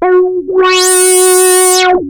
OSCAR  9 F#4.wav